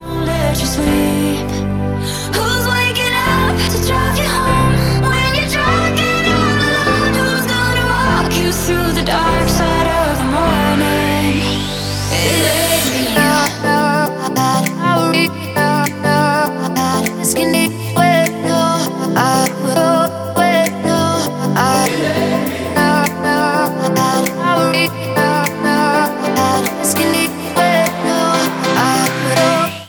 • Pop
An EDM, dance-pop, electropop and tropical house song